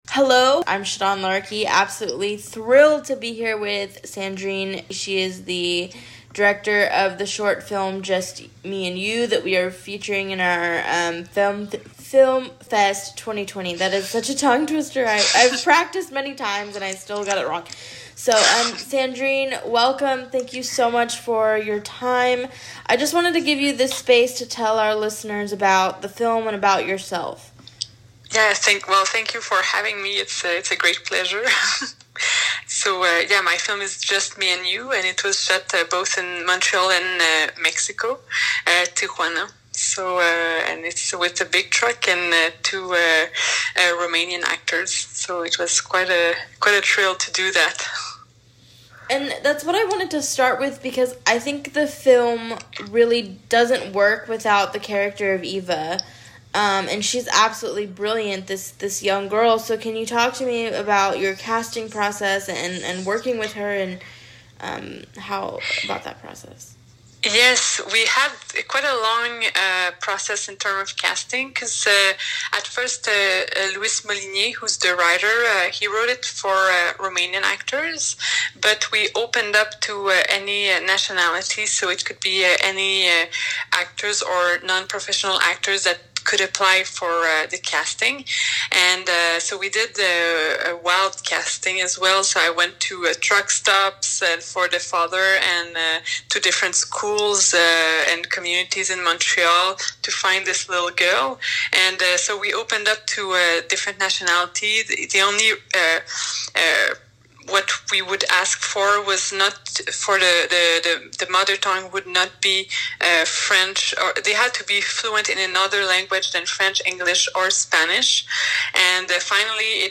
Listen to the audio interview below as well as a chance to see the short film.